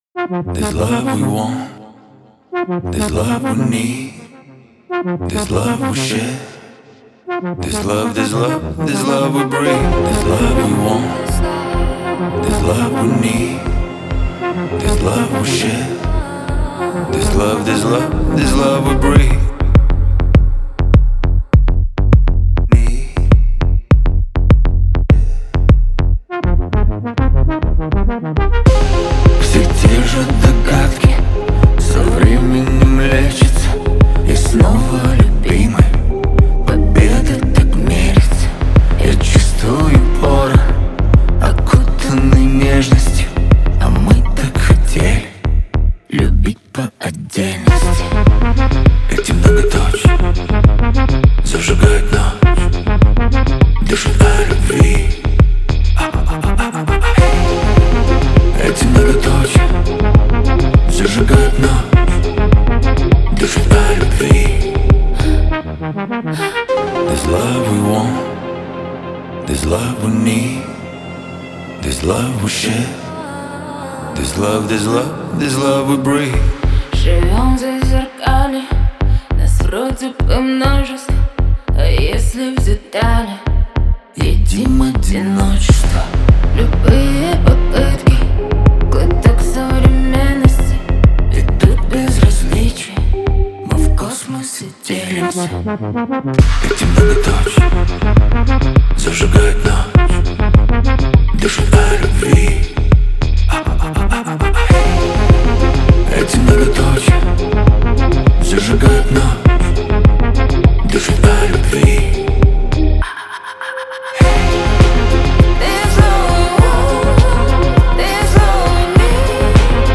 романтическая поп-композиция